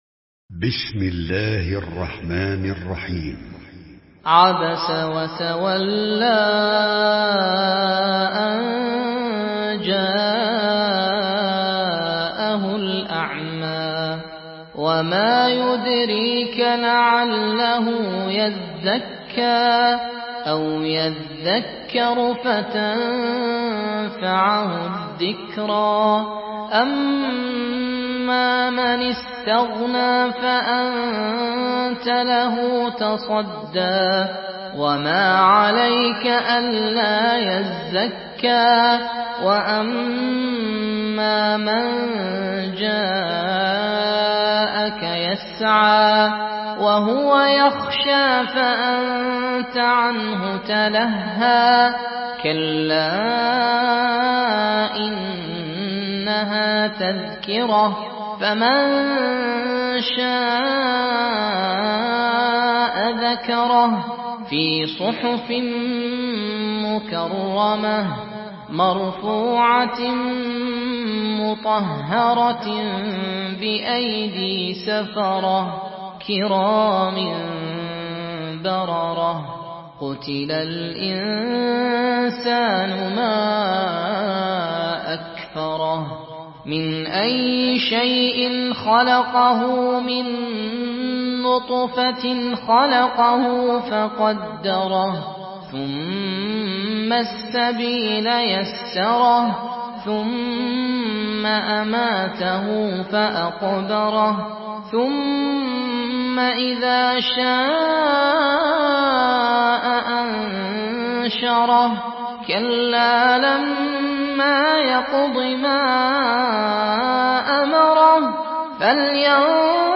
مرتل
تلاوت ها